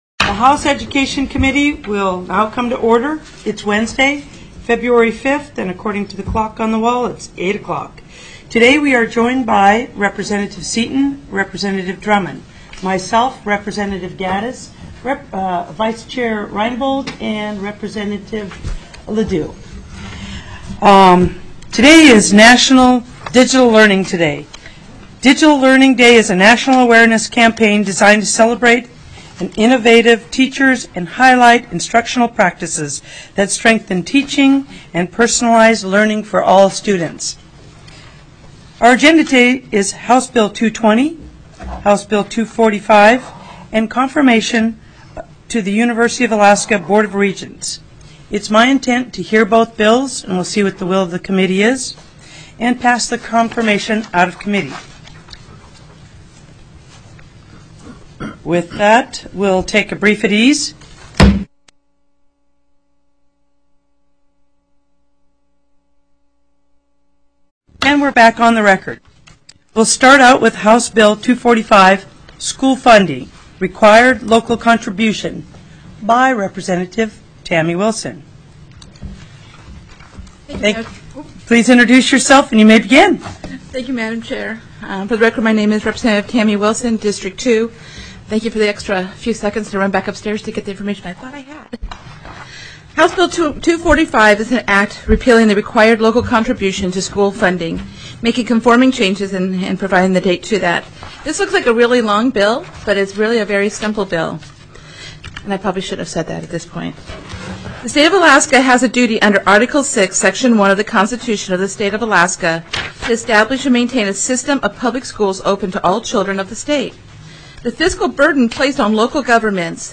Confirmation Hearing
TELECONFERENCED